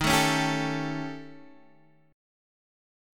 D#7b5 chord